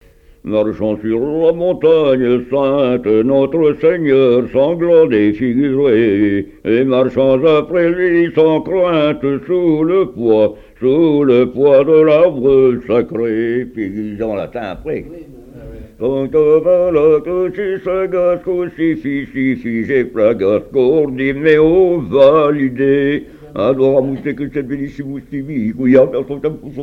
cantique
collecte en Vendée
Pièce musicale inédite